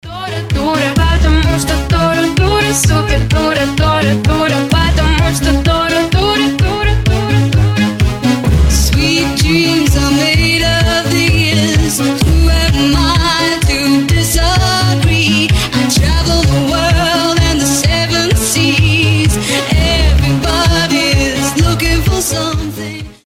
• Качество: 320, Stereo
Mashup
ремиксы